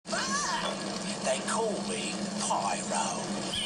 Pyro Sound Bytes
From the X-Men Animated Series.